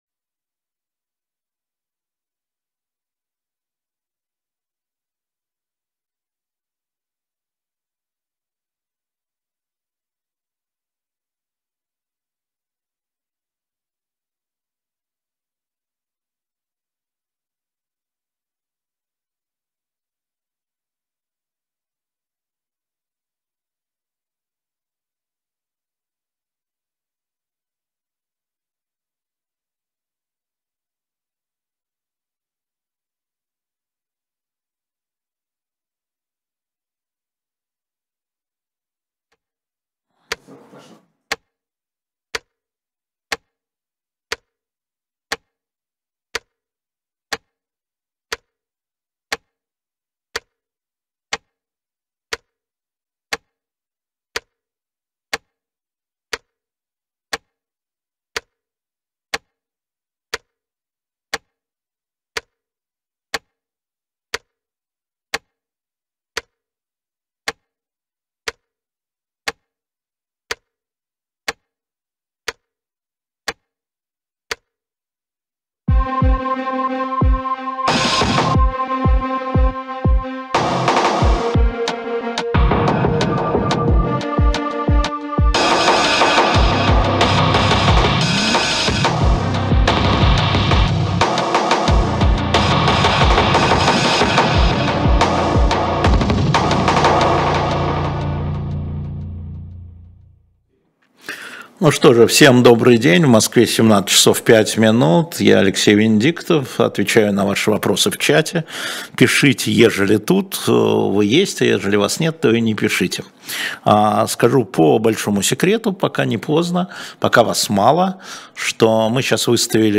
На ваши вопросы в прямом эфире отвечает Алексей Венедиктов